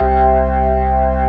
55O-ORG06-C2.wav